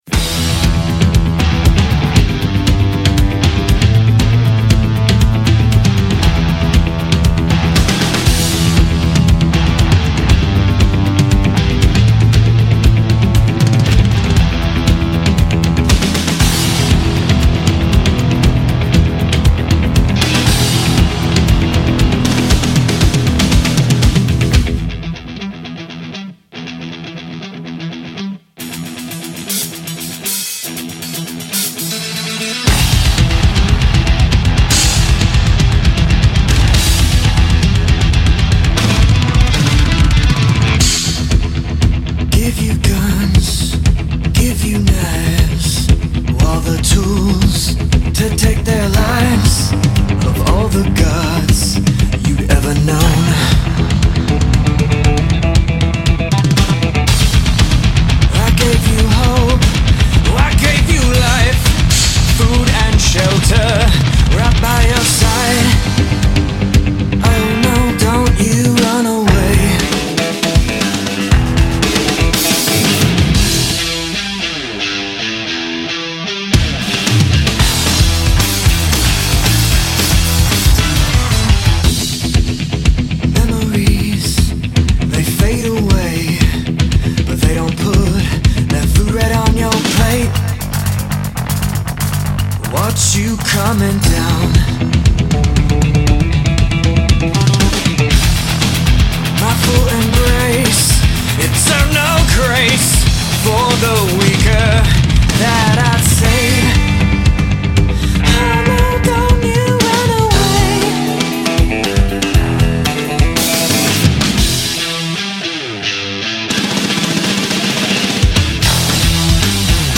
progressive rock band